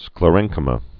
(sklə-rĕngkə-mə)